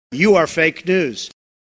На этой странице собраны аудиозаписи с голосом Дональда Трампа: знаменитые высказывания, фрагменты выступлений и публичных речей.